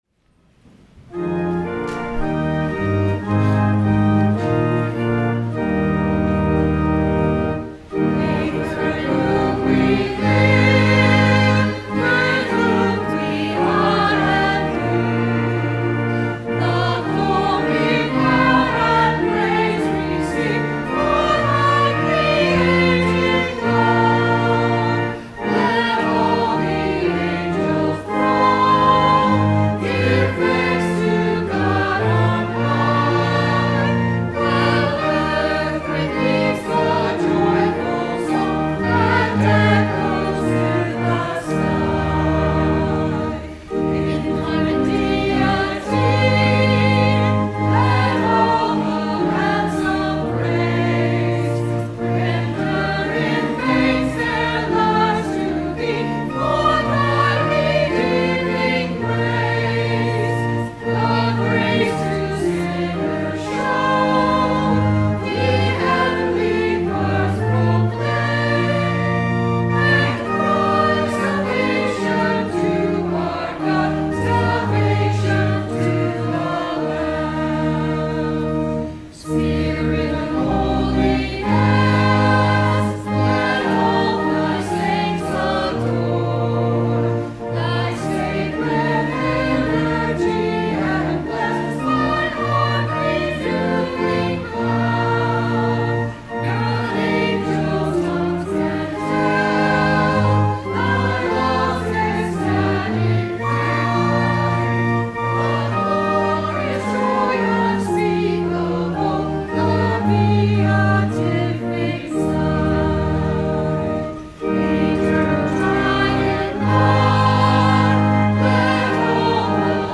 Sung by the Church and Choir.
Hymn